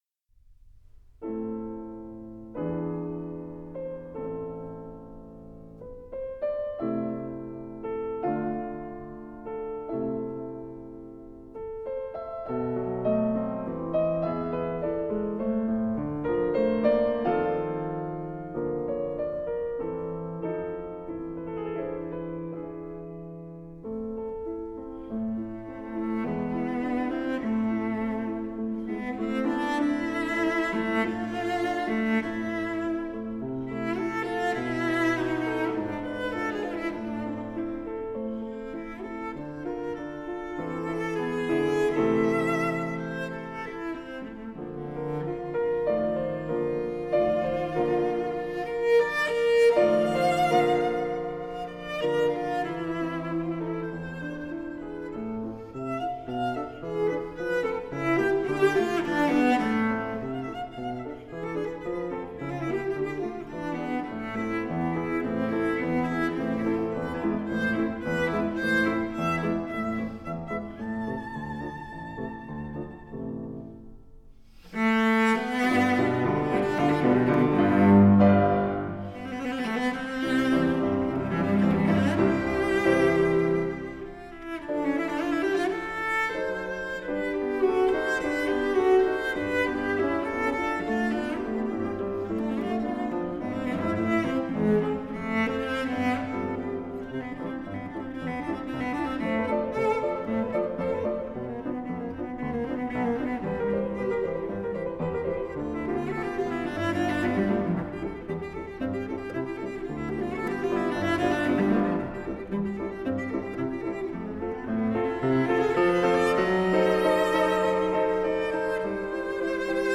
Piano Cello Duo
Works for cello and piano
The two play together as a seamless, homogenous unit.